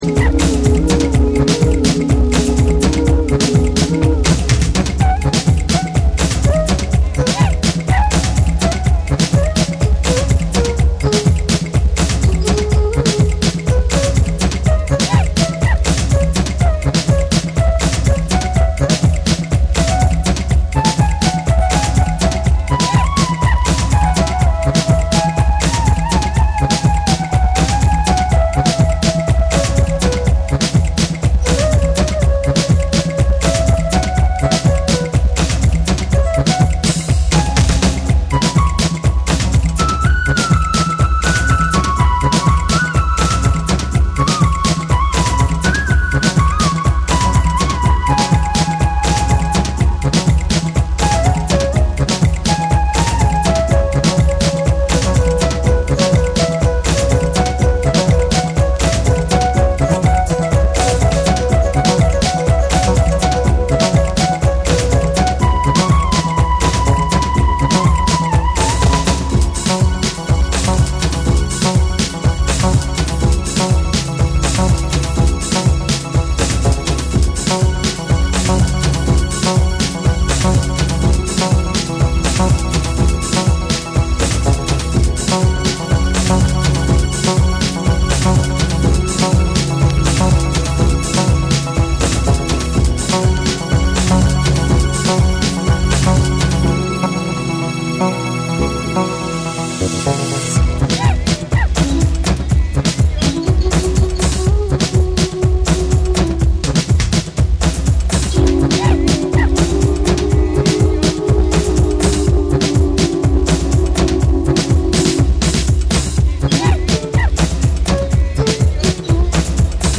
パーカッシヴでダンサブルなトラックを下敷きにフルートやシンセが交わるスピリチュアルなハウス・ナンバーを披露する
JAPANESE / TECHNO & HOUSE / ORGANIC GROOVE